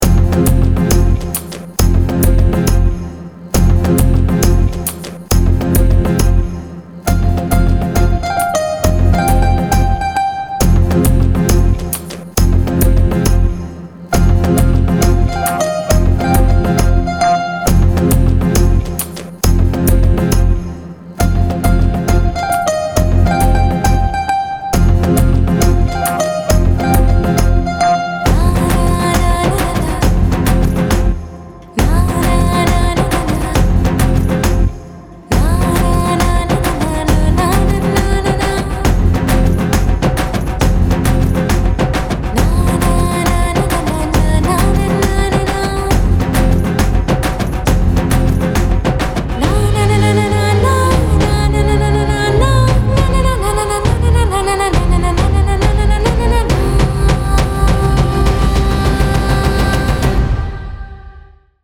without dialogues and disturbing sounds